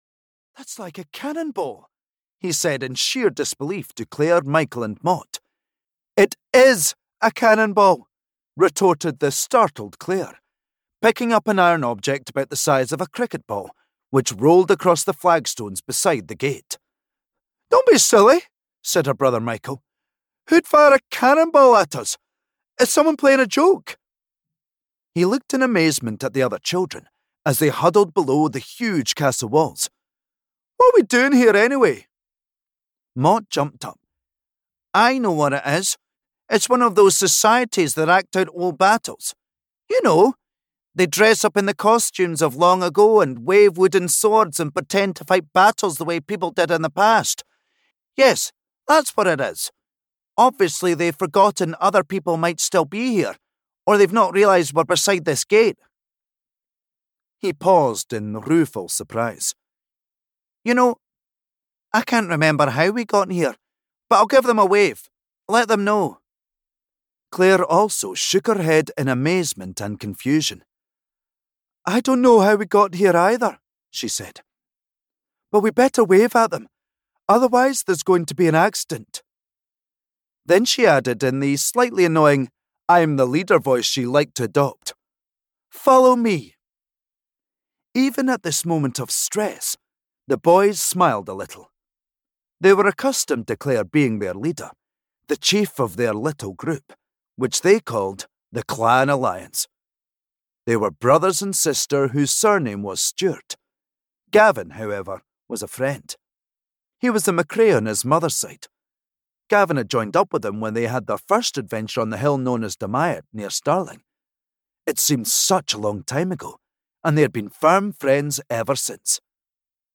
Jewels on the Move (EN) audiokniha
Ukázka z knihy